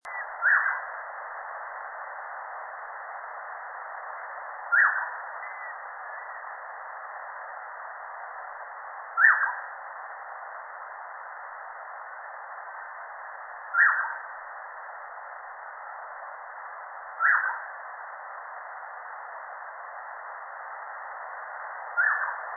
大彎嘴 Pomatorhinus erythrocnemis
南投縣 水里鄉 大觀發電廠
錄音環境 闊葉林
行為描述 鳥叫
收音: 廠牌 Sennheiser 型號 ME 67